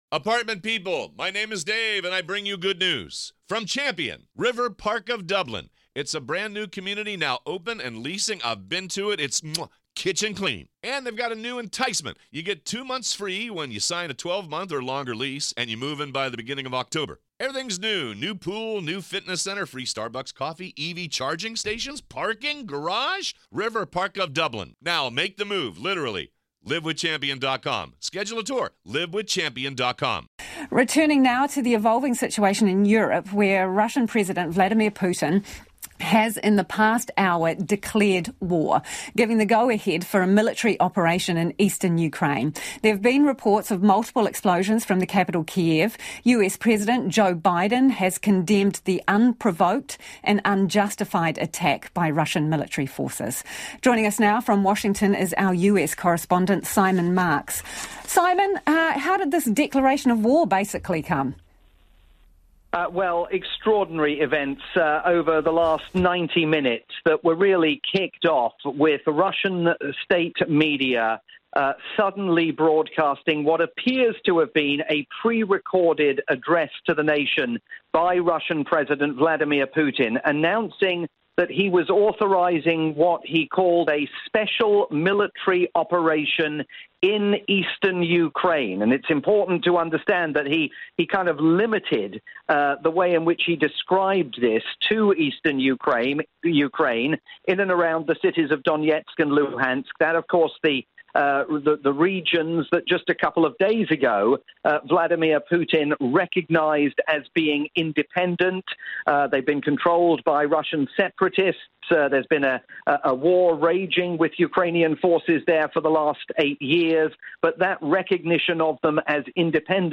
live, breaking news report